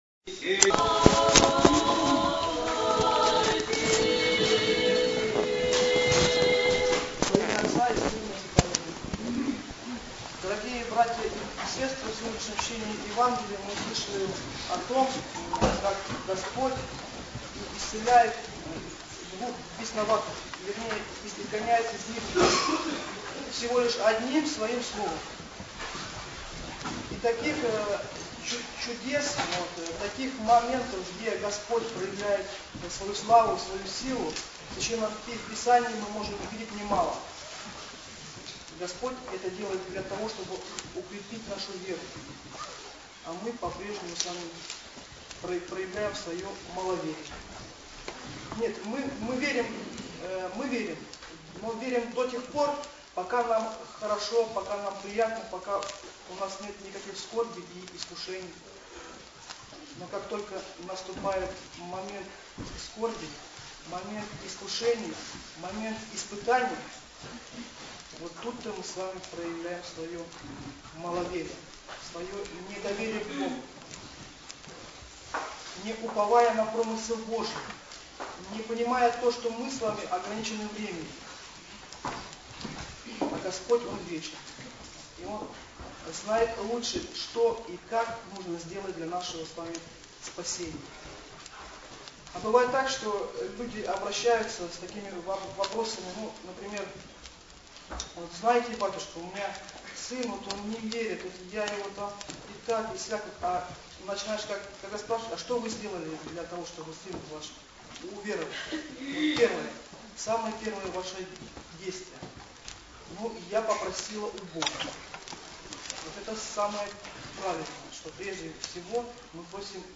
Вот и сегодня, на воскресной Литургии служила только «молодёжь» — трое отцов, «старики» же в основном исповедовали.
Запись опубликована в рубрике веб-проповедь с метками .